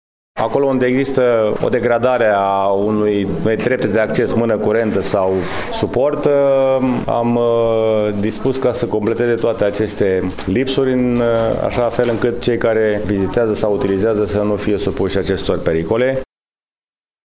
George Scripcaru, primar: